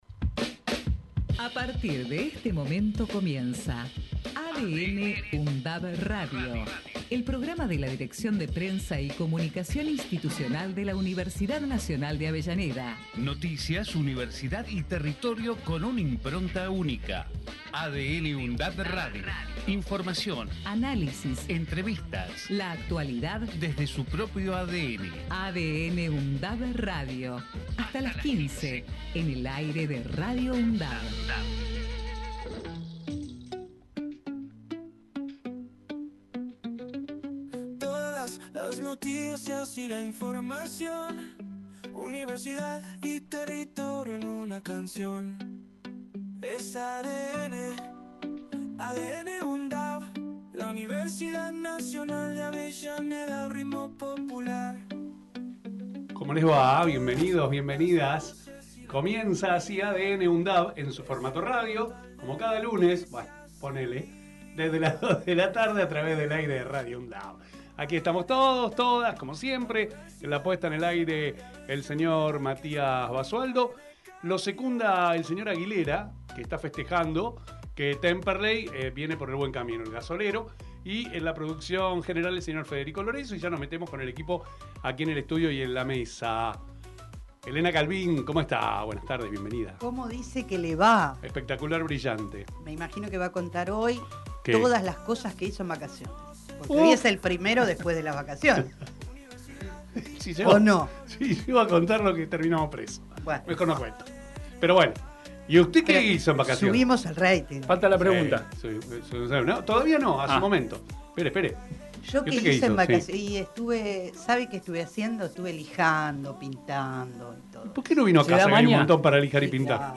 Texto de la nota: El programa de la Dirección de Prensa y Comunicación Institucional de la Universidad Nacional de Avellaneda en su emisora Radio UNDAV, busca transmitir la impronta de la Universidad, su identidad, su ADN de una forma actual y descontracturada, con rigurosidad y calidad informativa. Noticias, universidad y territorio son los tres ejes que amalgaman la nueva propuesta a través de la imbricación y la interrelación de las temáticas que ocupan y preocupan a la comunidad local, zonal y nacional desde una mirada universitaria, crítica y constructiva a través de voces destacadas del mundo académico, político, cultural y social.